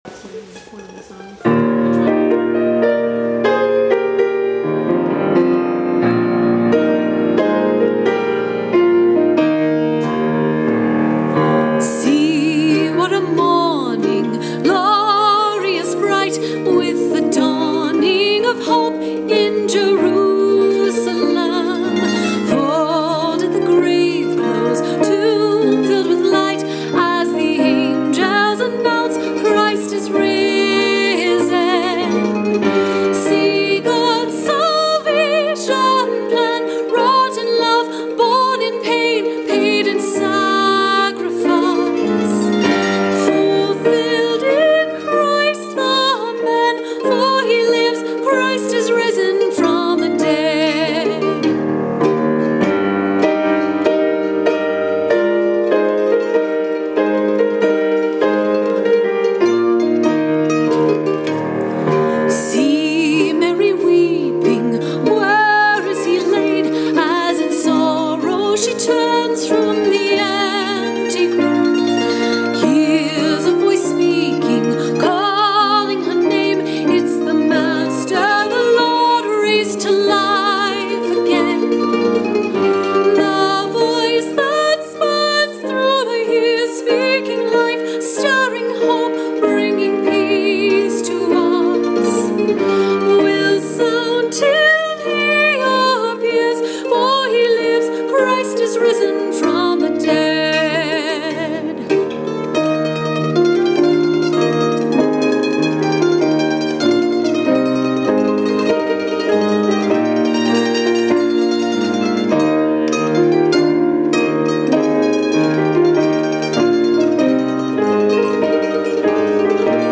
Musical Performance See What a Morning